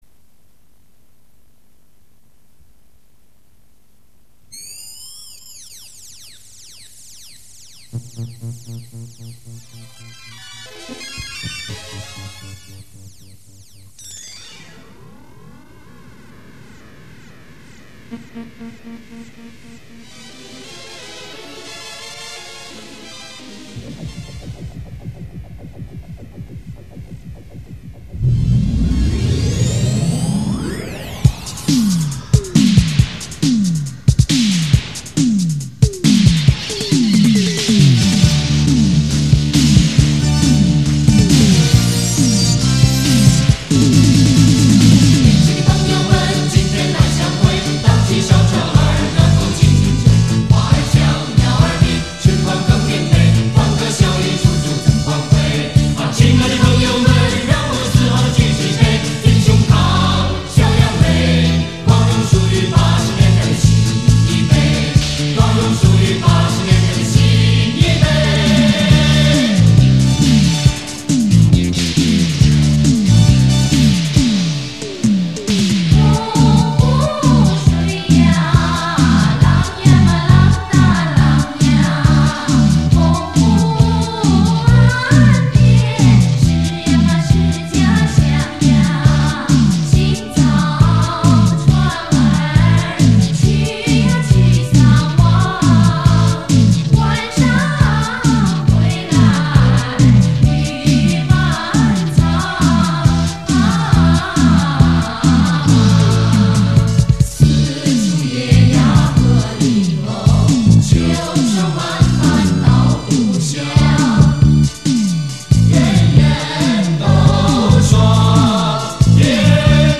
在线试听：A面片段 [128K mp3]